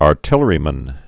(är-tĭlə-rē-mən)